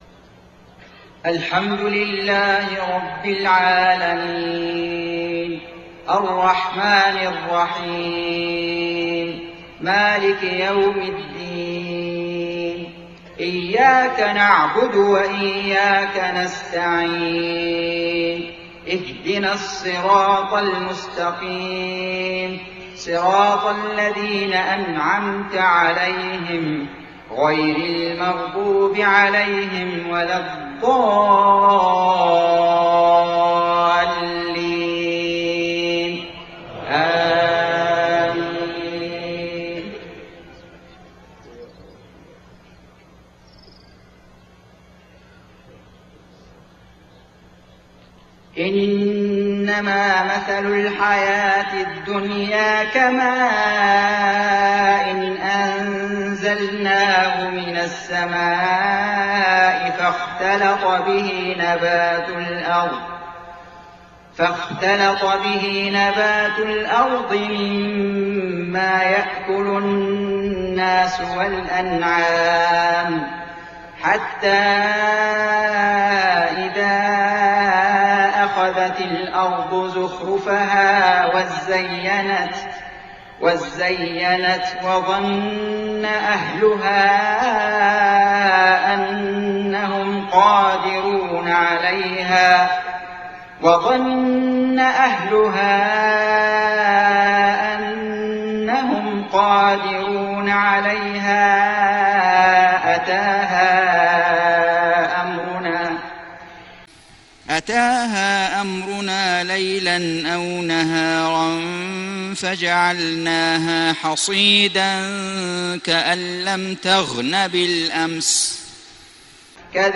صلاة المغرب محرم 1429هــ من سورة يونس 24-27 > 1429 🕋 > الفروض - تلاوات الحرمين